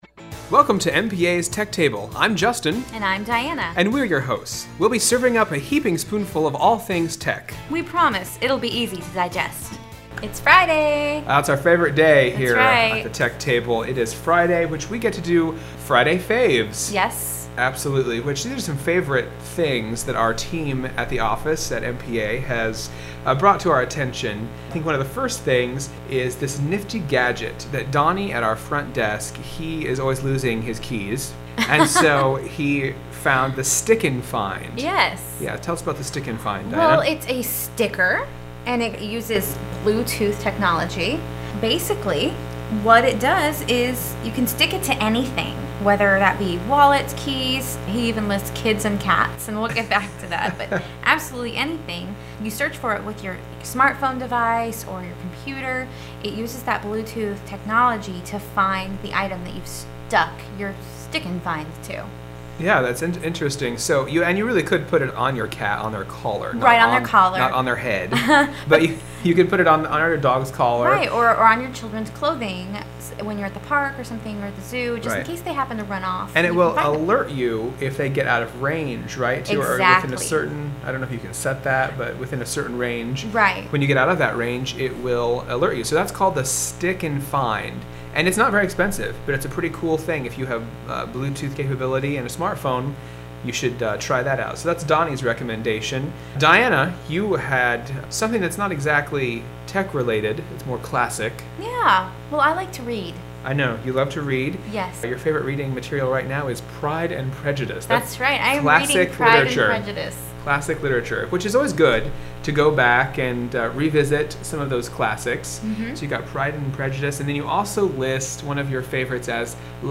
Tech Table Radio Show